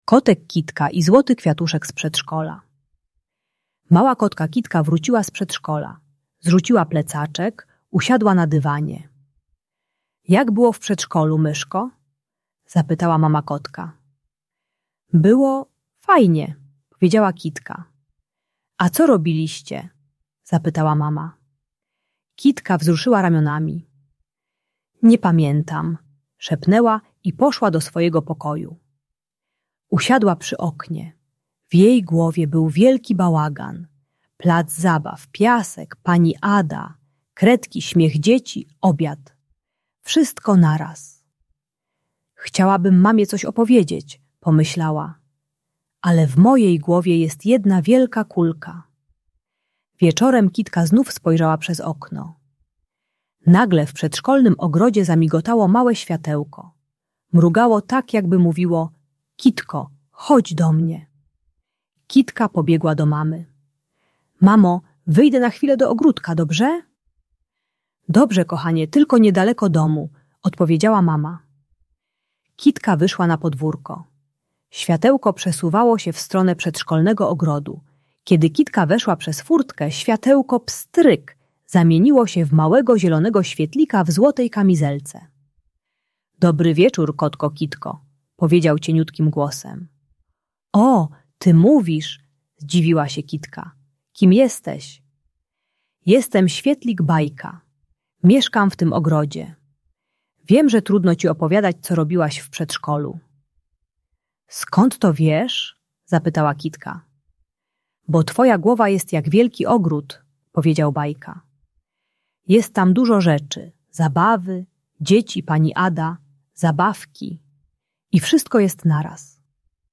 Kotek Kitka i Złoty Kwiatuszek - Przedszkole | Audiobajka